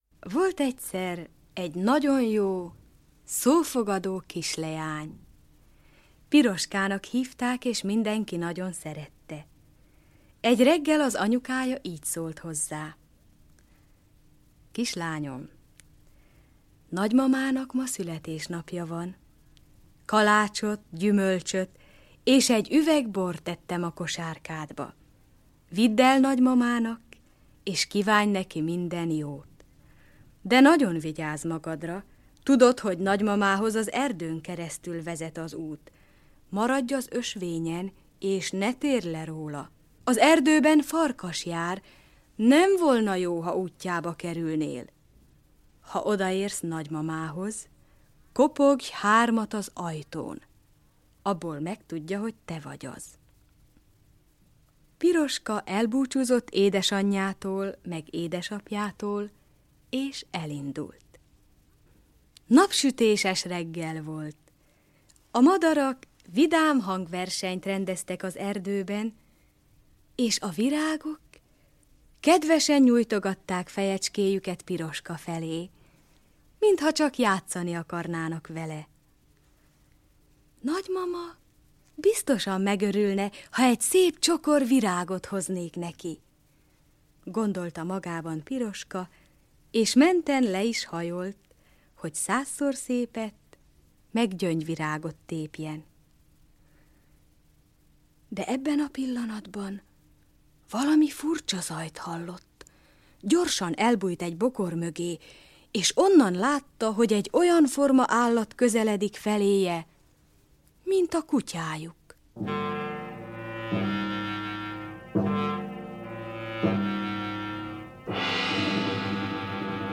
Piroska és farkas, A repülö börönd, Hófehérke, Terülj, asztalkám, Hamupipöke - Autor neznámý - Audiokniha